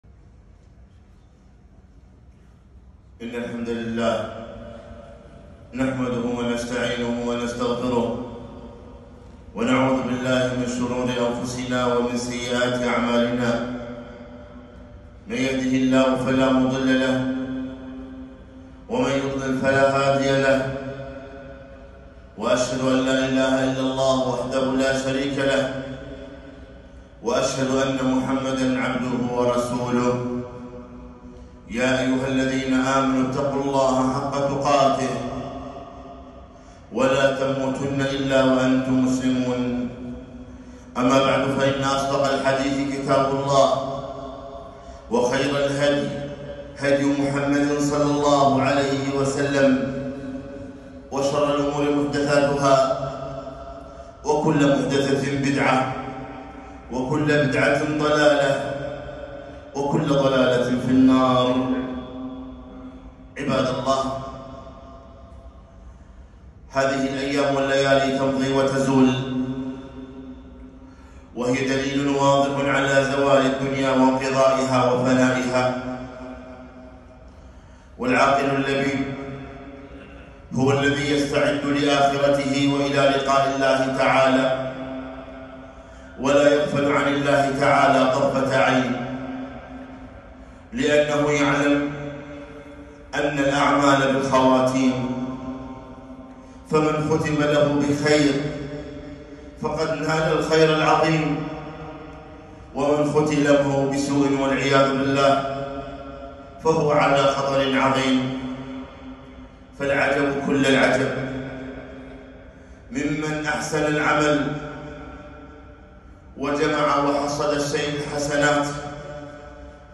خطبة - الثبات على الطاعات